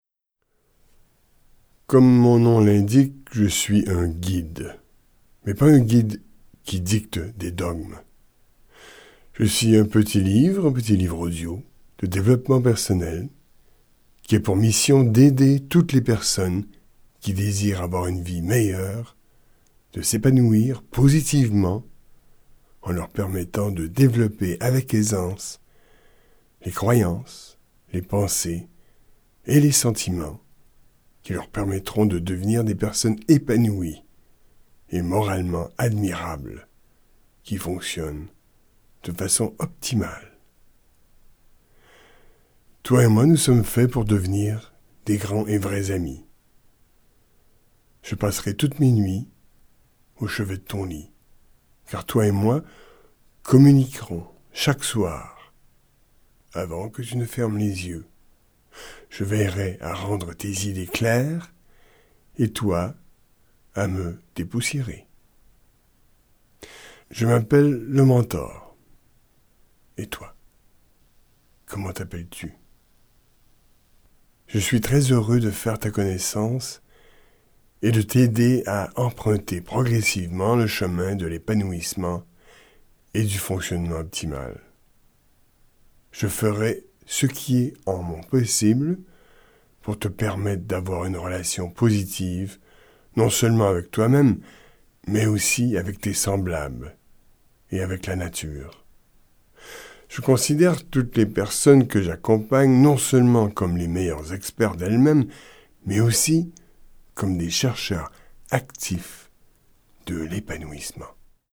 Le mentor est un livre audio de développement personnel qui a pour mission d’aider toutes les personnes qui désirent avoir une vie meilleure et de s’épanouir positivement en leur permettant de développer avec aisance, les croyances, les pensées et les sentiments qui leur permettront de devenir des personnes épanouies et moralement admirables fonctionnant de façon optimale.